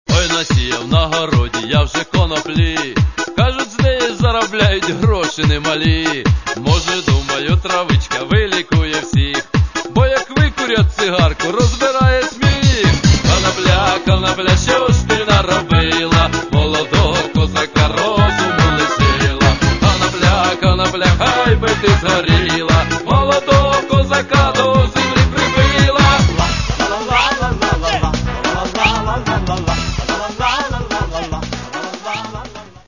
Каталог -> MP3-CD -> Эстрада
Чтобы эти самые развлечения происходили весело и живенько.